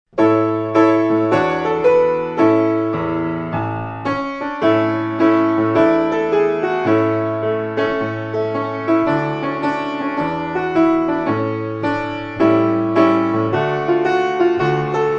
New Year's Day song for children
▪ Instrumental sing-along track in MP3 format
Listen to a sample of the instrumental track.